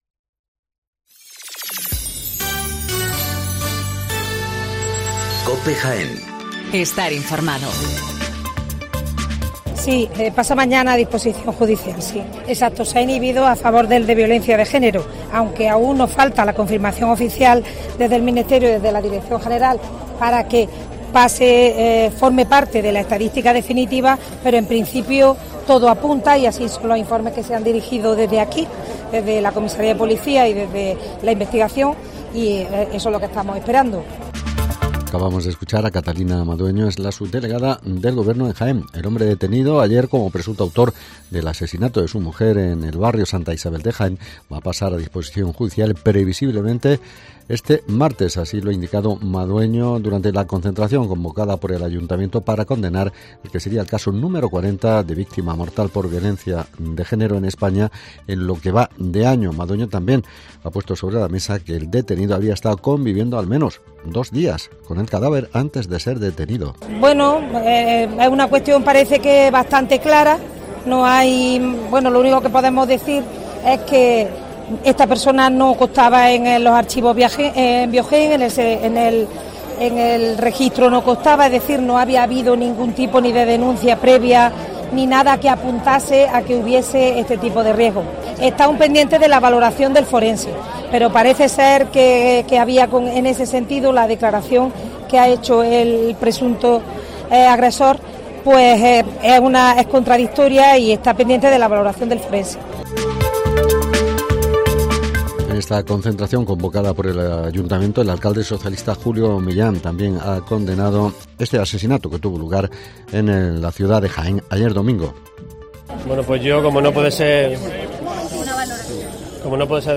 Toda la actualidad, las noticias más próximas y cercanas te las acercamos con los sonidos y las voces de todos y cada uno de sus protagonistas.